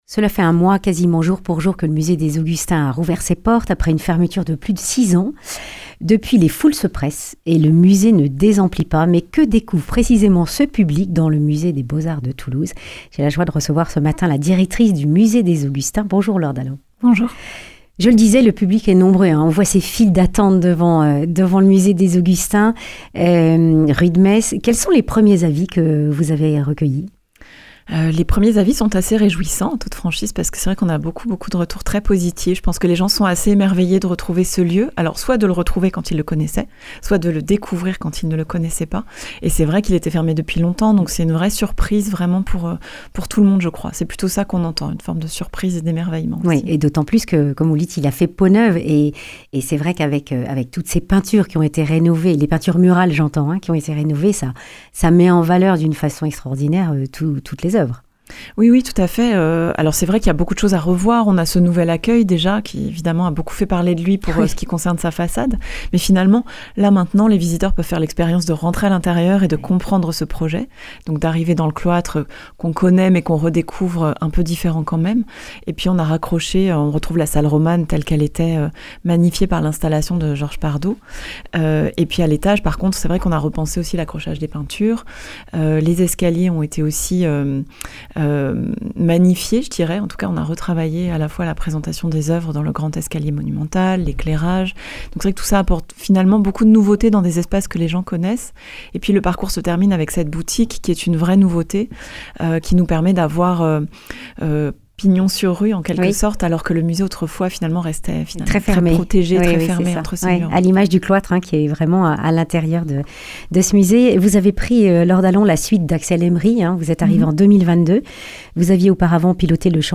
Accueil \ Emissions \ Information \ Régionale \ Le grand entretien \ Le musée des Augustins, quel bilan de la fréquentation un mois après sa (…)